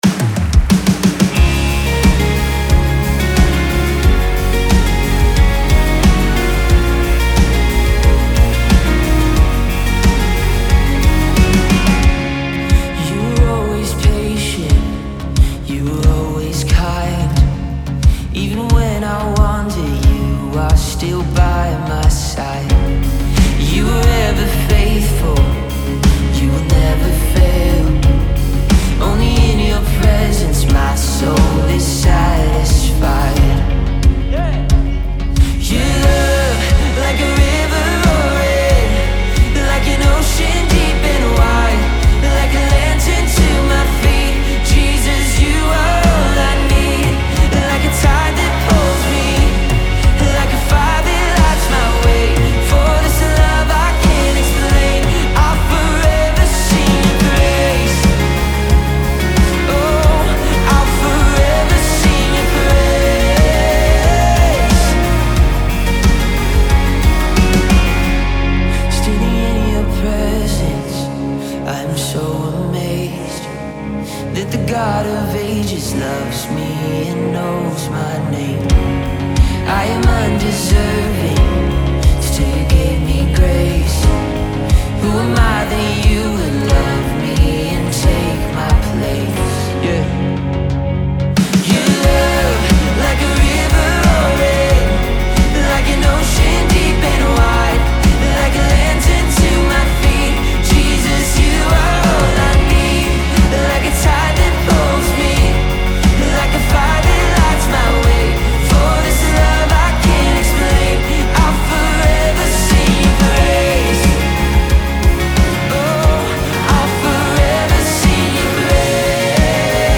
2-Forever-Sing-Your-Praise-Live.mp3